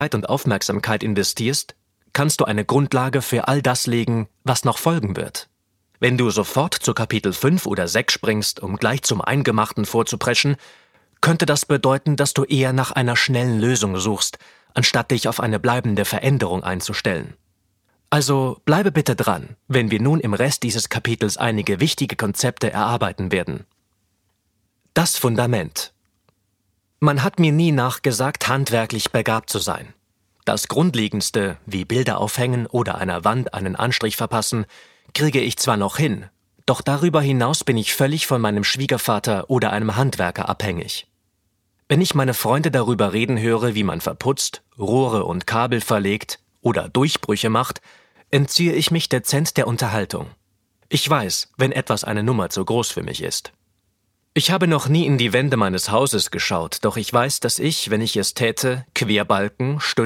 • Sachgebiet: Hörbücher